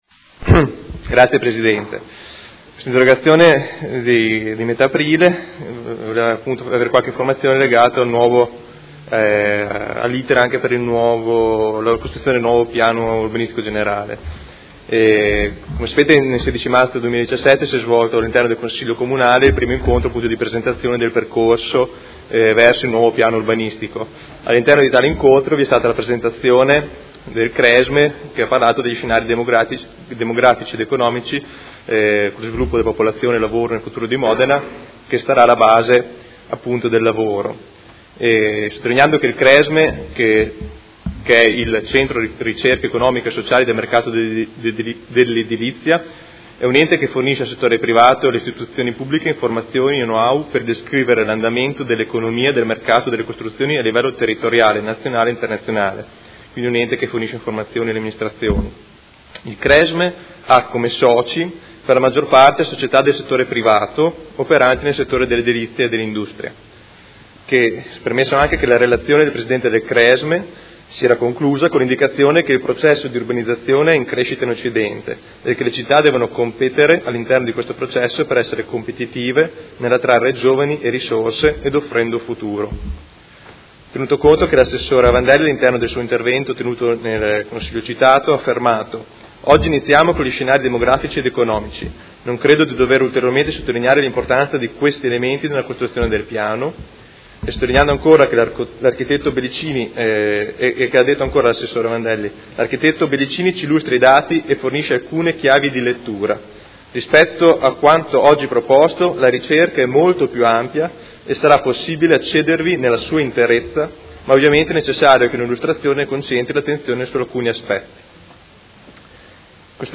Seduta del 01/06/2017. Interrogazione del Gruppo Movimento cinque Stelle avente per oggetto: Scenari demografici in vista del nuovo PSC-PUG